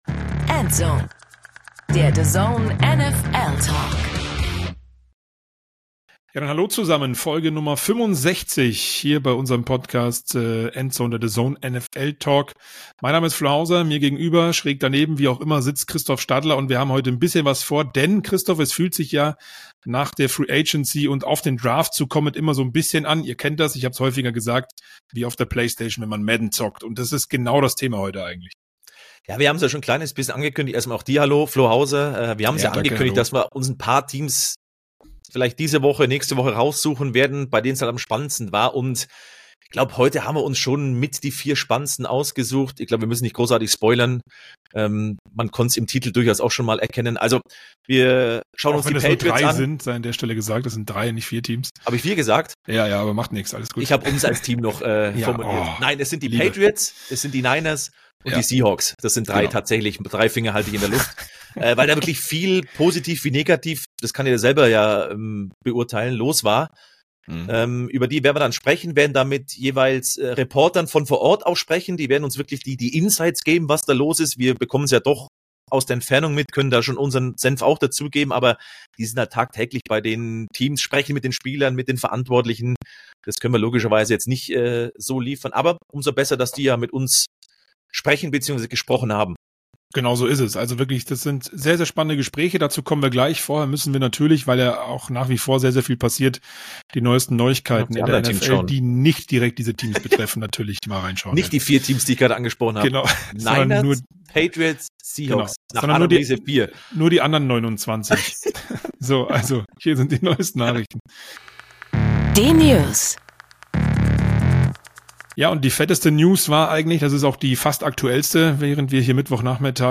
Dazu haben wir mit drei Reportern aus den USA gesprochen, um tiefe Einblicke zu bekommen, denn die sind sehr nah dran, an den besagten Teams.